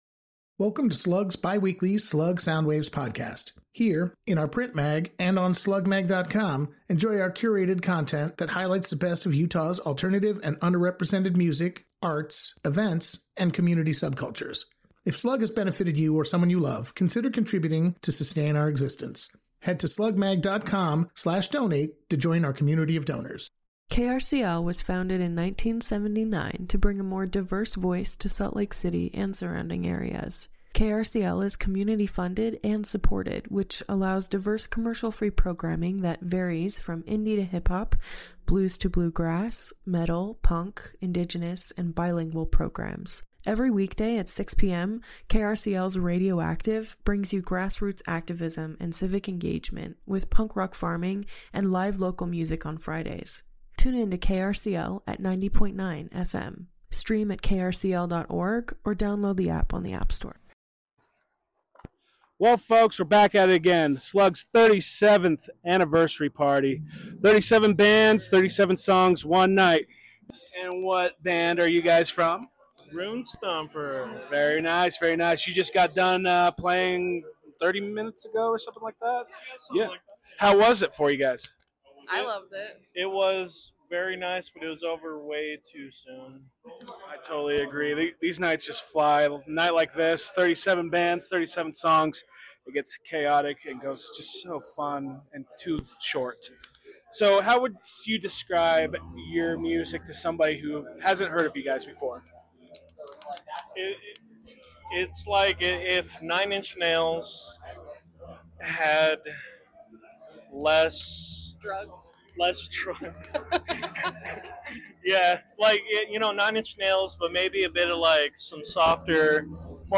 Hear from Runestomper, Secondhand Sun, Shecock, St Moan, Sylke and Wasatch Valley Drifters in this episode of SLUG Soundwaves — plus live performance recordings